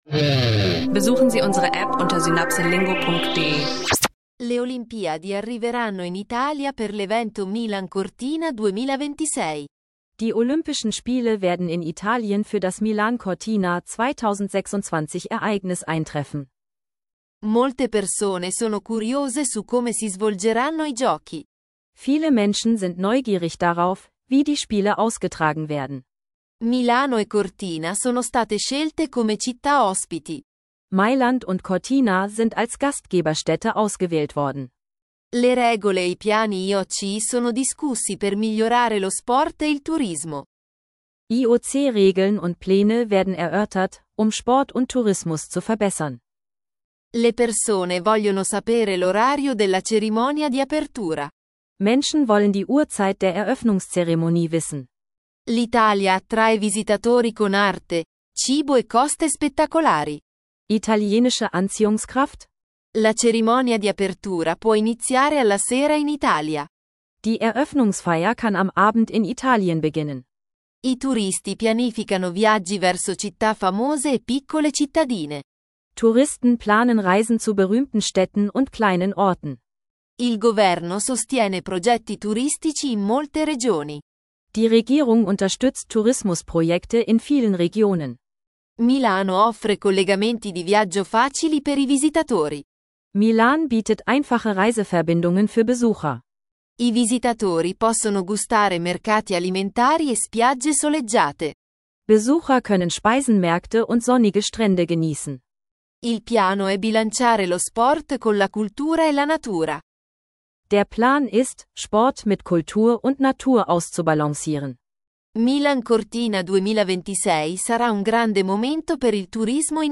Ein KI-unterstützter Italienisch-Sprachkurs über Mailand-Cortina 2026, Tourismus, Olympia-Planung und aktuelle Gesellschaftsthemen.